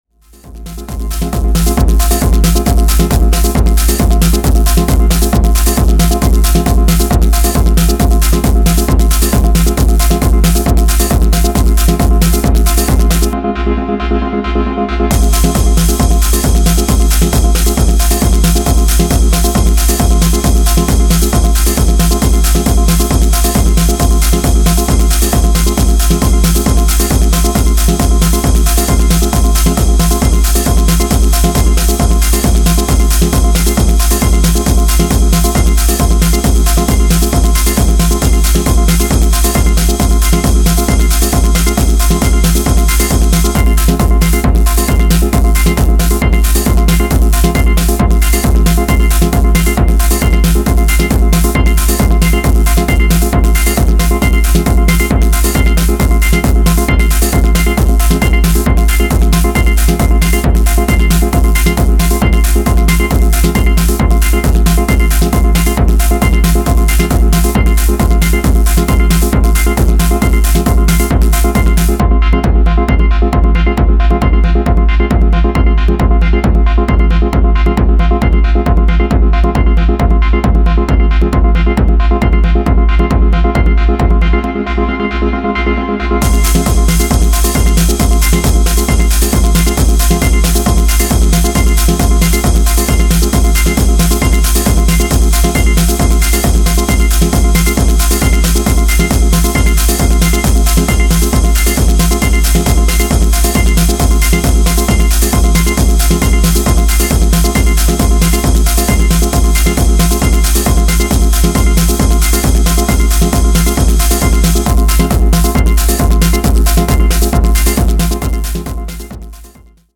FUNCTIONAL TECHNO CRAFTED TO ELEVATE